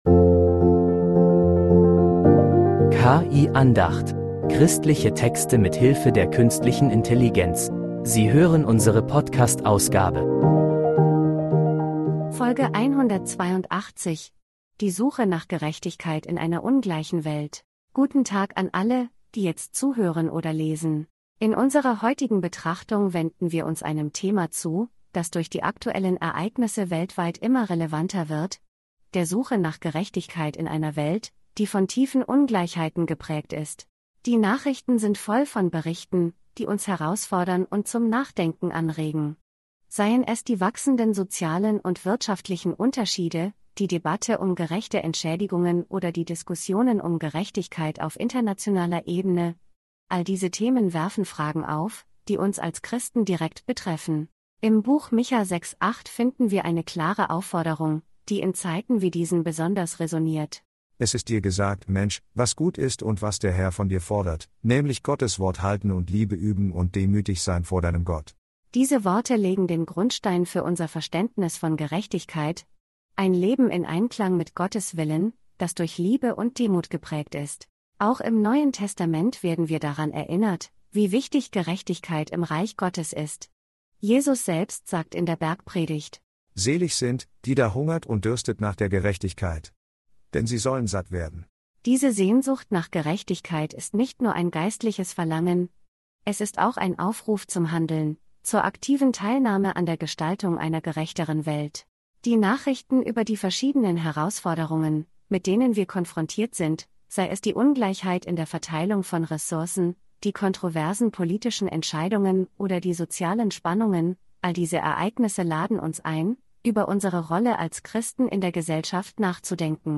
Christliche Texte mit Hilfe der Künstlichen Intelligenz
Diese Predigt ruft uns dazu auf, in einer Welt voller